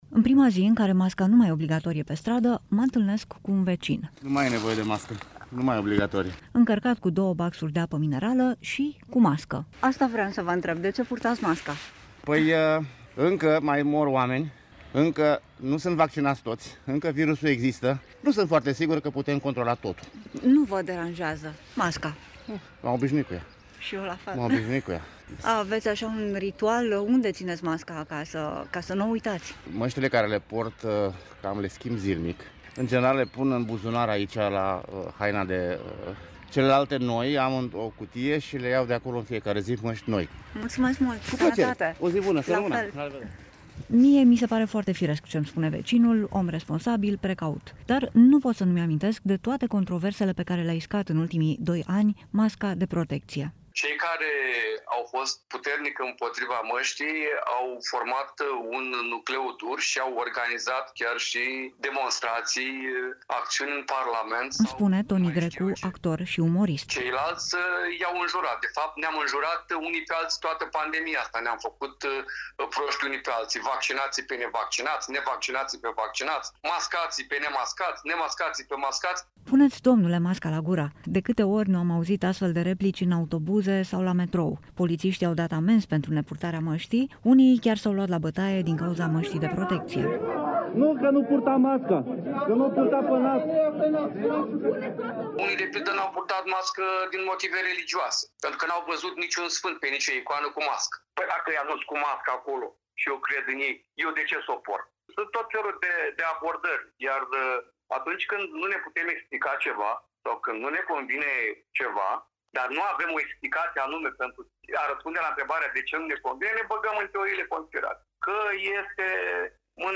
Reportaj-Lumea-Fara-Masca.mp3